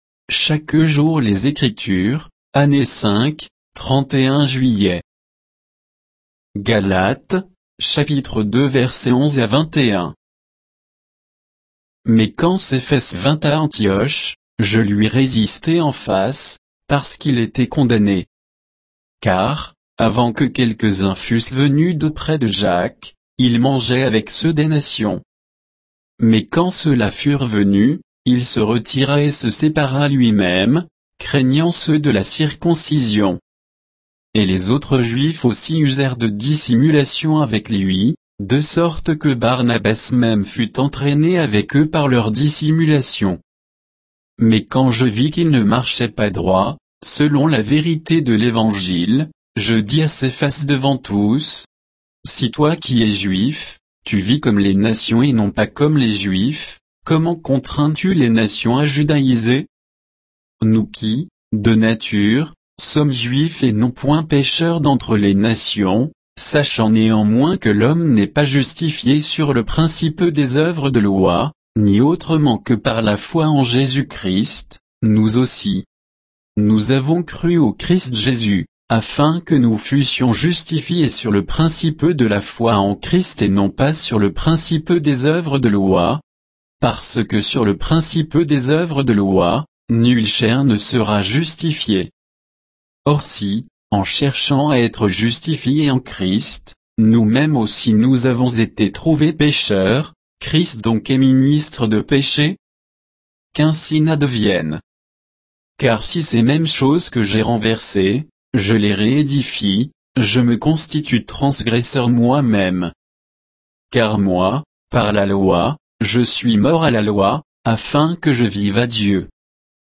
Méditation quoditienne de Chaque jour les Écritures sur Galates 2